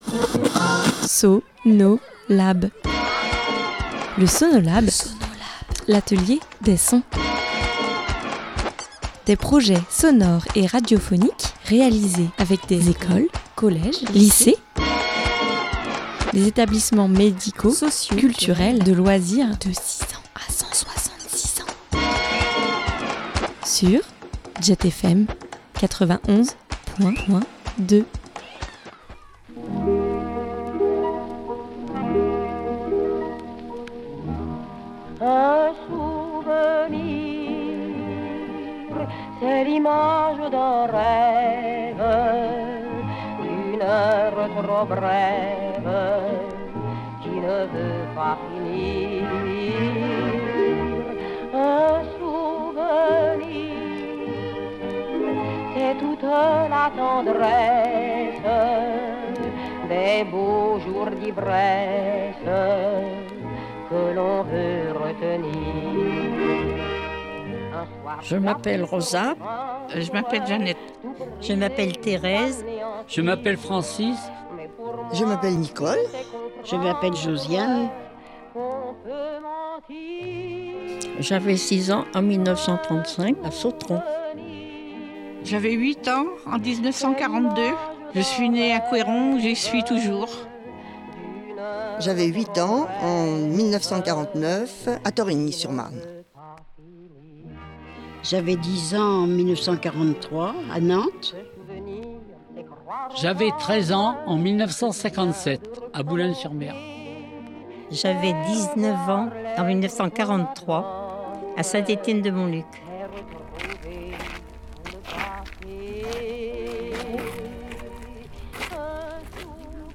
Ils se sont enregistrés en studio, ont réalisé des bruitages à partir d’anciens objets personnels et ont amené leurs vinyles pour habiller leur création.
Ils ont raconté, chanté, rigolé, enregistré.